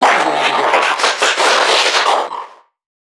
NPC_Creatures_Vocalisations_Infected [39].wav